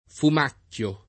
fumacchio [ fum # kk L o ] s. m.; pl. -chi